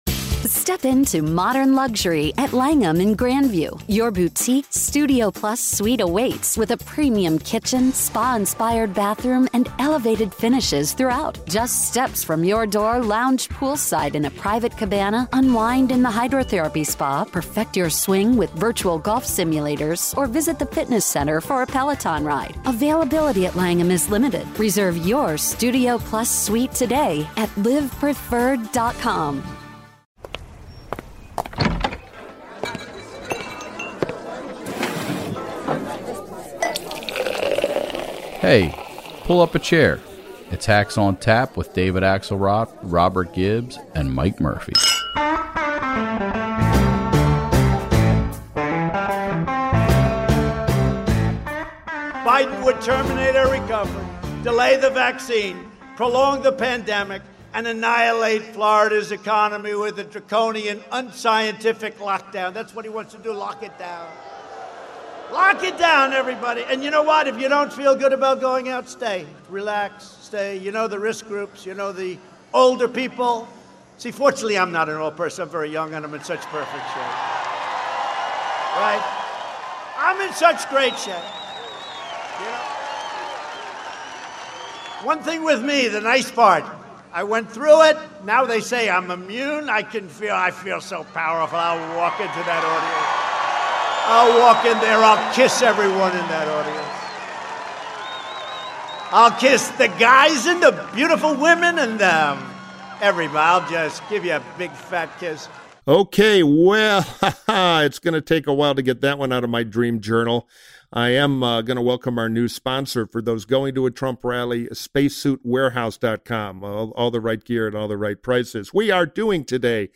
Amy Walter, the national editor of the Cook Political Report, joins Murphy and Gibbs to discuss the impact of the Supreme Court confirmation hearings and the state of the 2020 Presidential race (yup, you guessed it, it’s stable). Together, they also walk through the most important Senate races we are all talking about to determine who will control the chamber in 2021.